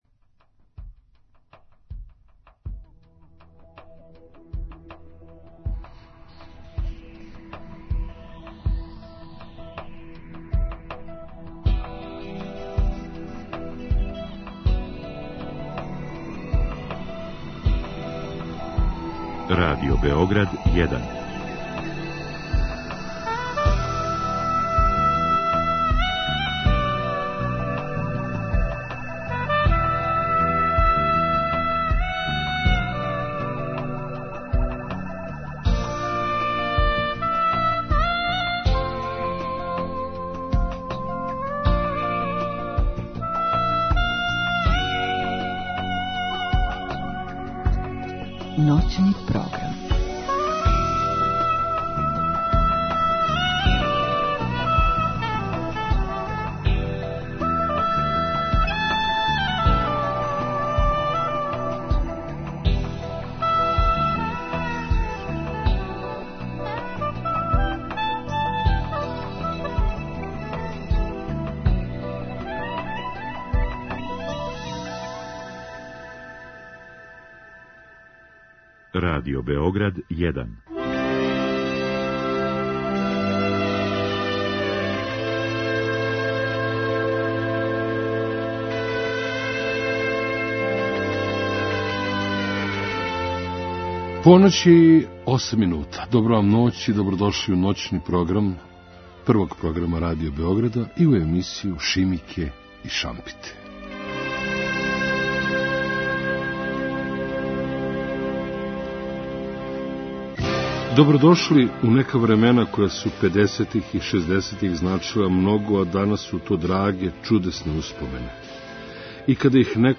Емисија Шимике и шампите са вама у ноћи између суботе и недеље доноси низ лепих сећања, догађаја, незаборавну музику.